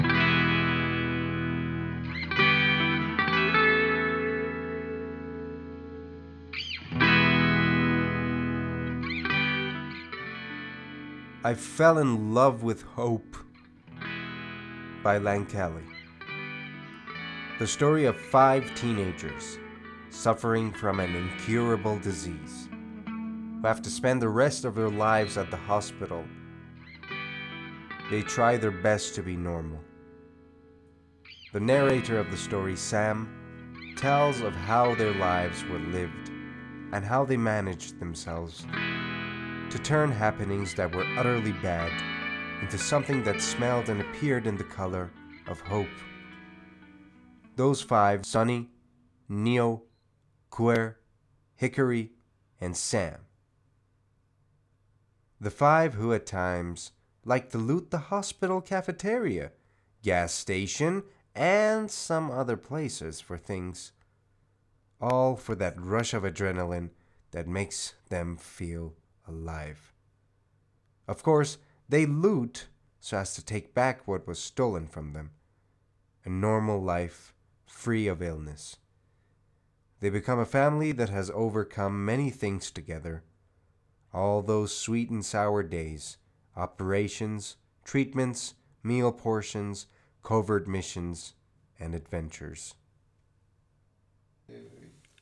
معرف صوتی کتاب I Fell in Love With Hope